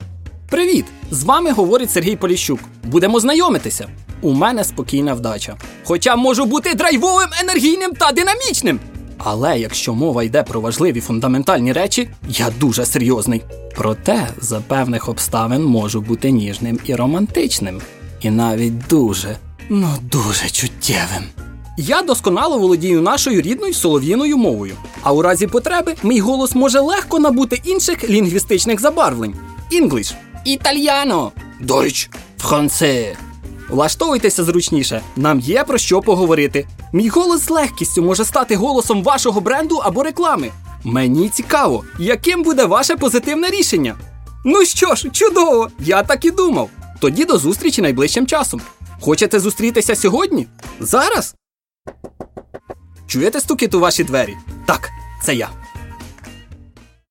Озвучування документального фільму «Федерер: останні 12 днів»
Голос: чоловічий, діапазонний, ігровий, яскравий, характерний, схильний до пародіювання.
Тенор